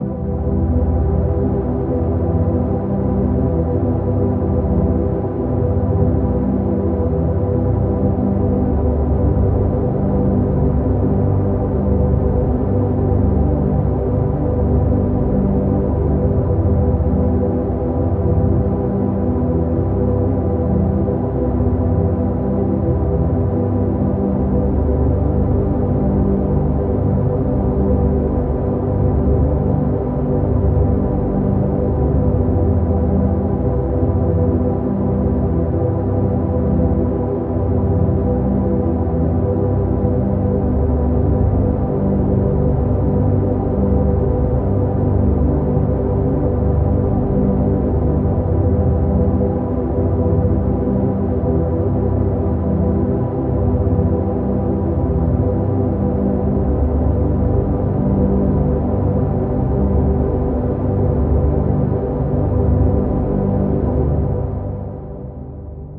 Hiệu ứng âm thanh 3D
Thể loại: Hiệu ứng âm thanh
Description: Hiệu ứng âm thanh tiếng vang dội gợi cho người nghe âm thanh như đánh từ bên trái sang bên phải, âm thanh nghe cứ như ảo giác nhưng lại gợi mở ra điều gì đó kì diệu, tuyệt vời, âm thanh gợi ra không gian đa chiều, đa sắc, như đến từ một cõi hư vô nhưng lại ẩn chứa những điều kì diệu, sáng tạo và bứt phá, âm thanh 3d vang vọng tạo cho người nghe cảm giác muốn khám phá, chinh phục.
Hieu-ung-am-thanh-3d-www_tiengdong_com.mp3